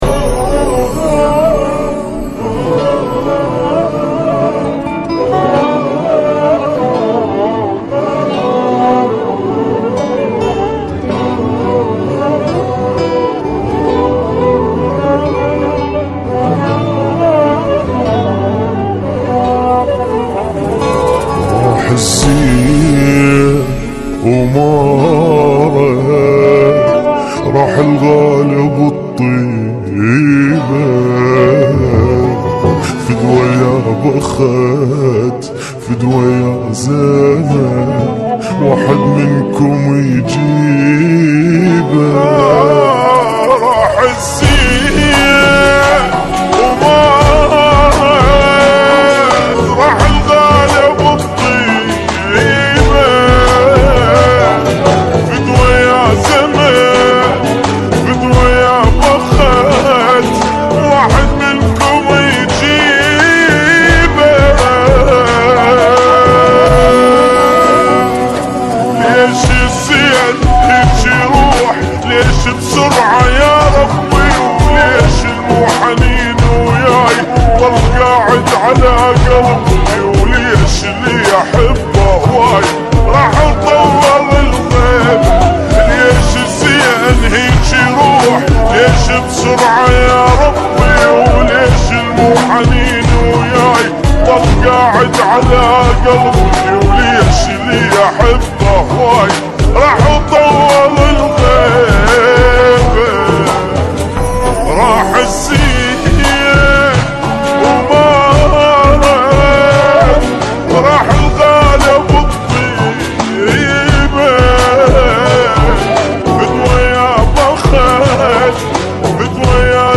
بطيء